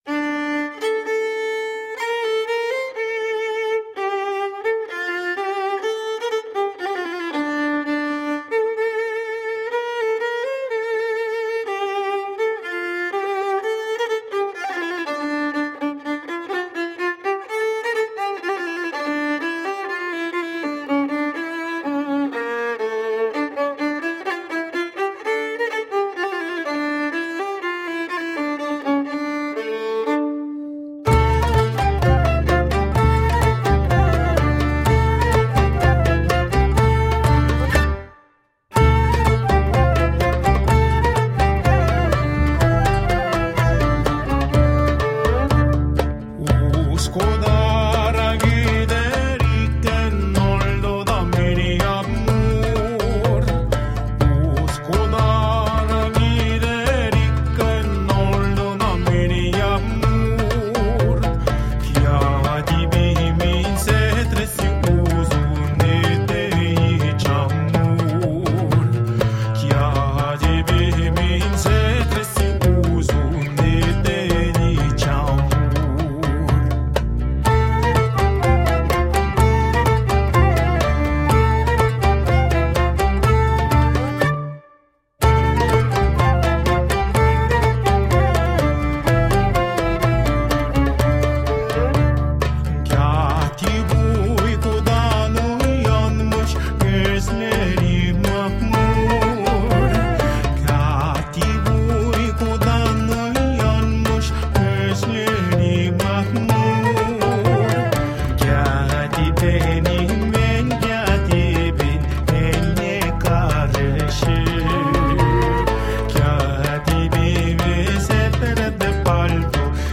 Sultry and percussive mid-east fusion.